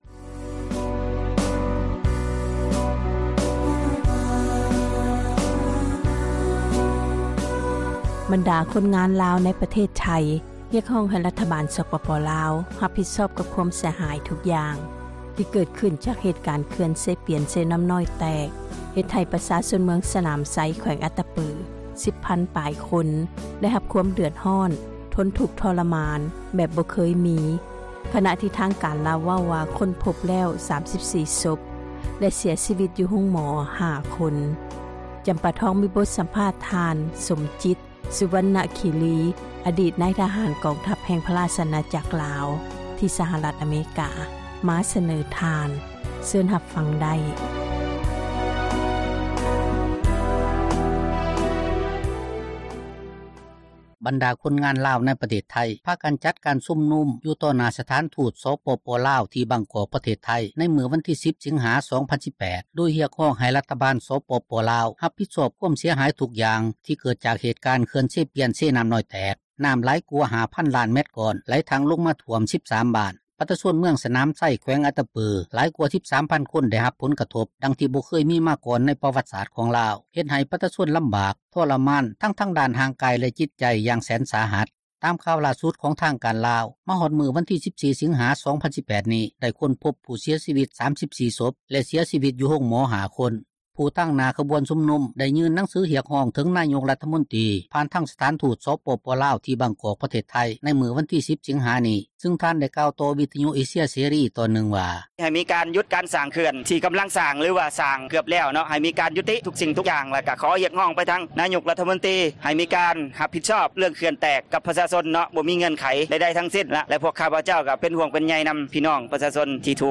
ສໍາພາດ ລາວໂພ້ນທະເລ: ເຂື່ອນແຕກ